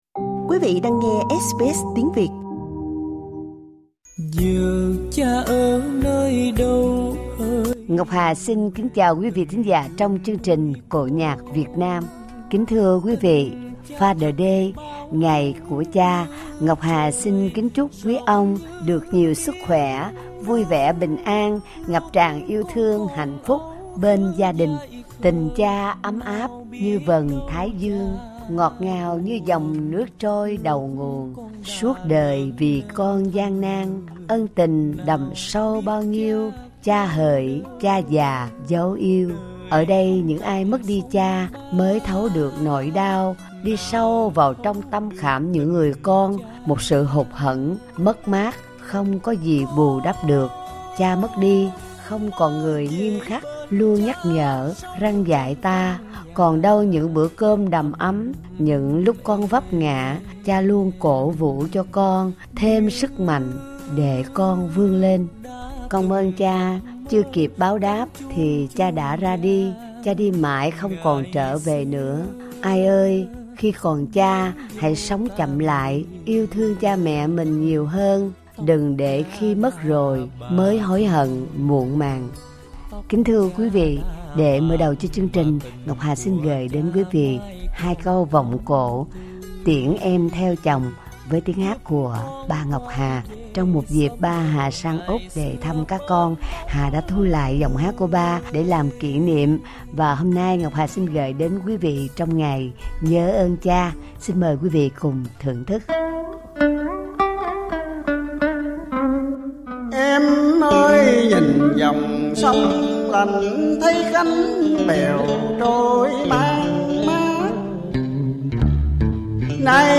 Xin mời quý vị thưởng thức chương trình cổ Nhạc đặc biệt nhân Father's Day.